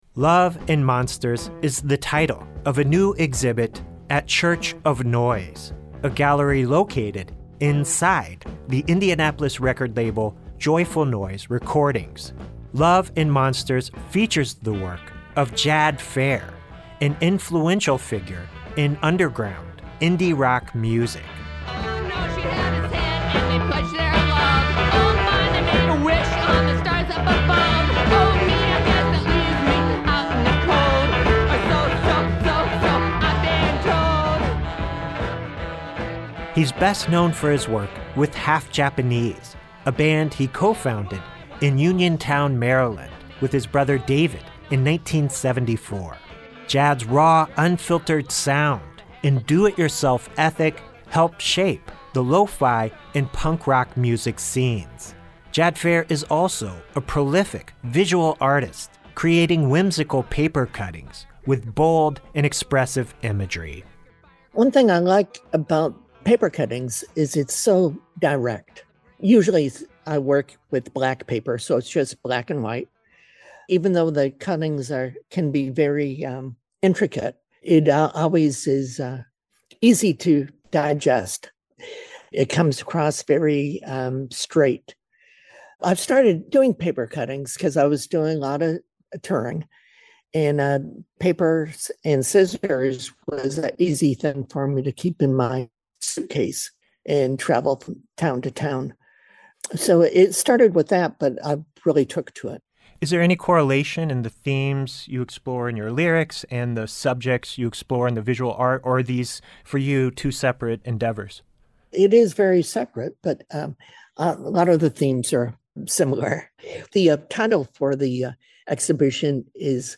Interview: DIY legend Jad Fair presents his visual art at Church of Noise